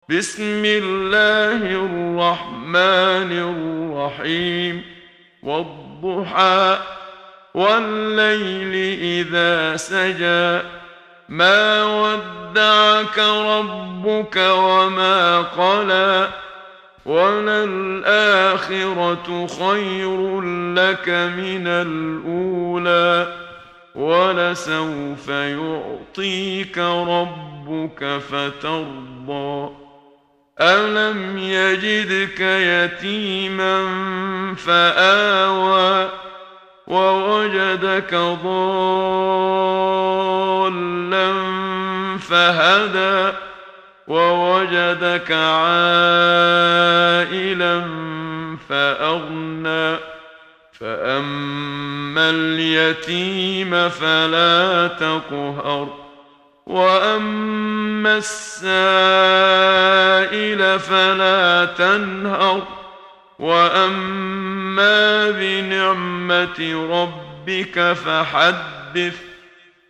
محمد صديق المنشاوي – ترتيل – الصفحة 8 – دعاة خير